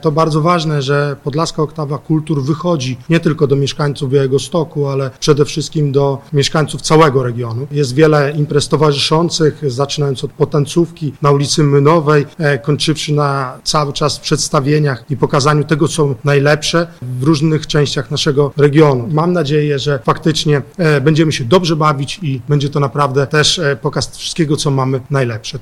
Marszałek Województwa Podlaskiego, Artur Kosicki mówił podczas konferencji dotyczącej tego wydarzenia, że to będzie okazja, aby wspólnie zjednoczyć kulturę całego świata.